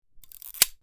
Rotating Sprinkler Cut, Garden, Outdoor Sound Effect Download | Gfx Sounds
Rotating-sprinkler-cut-garden-outdoor.mp3